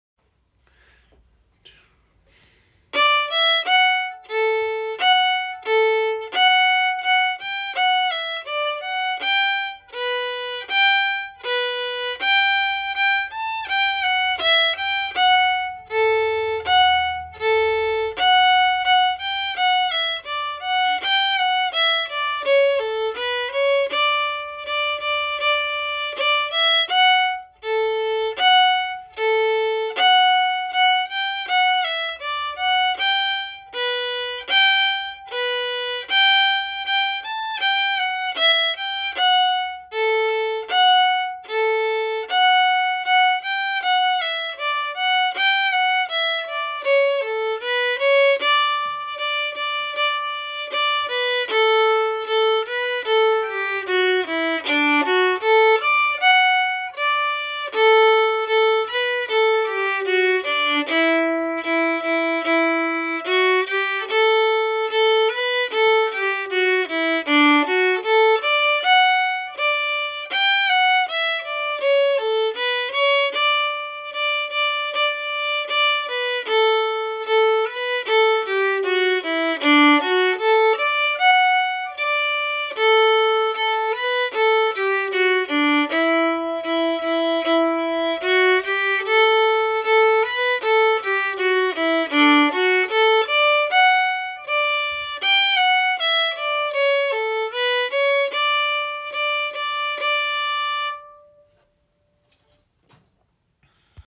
Liberty slow (audio MP3)Download